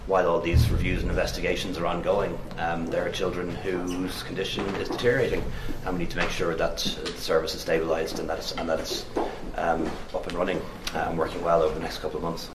He made the comment while attending Fine Gael’s Small Business and Enterprise Conference in Waterford.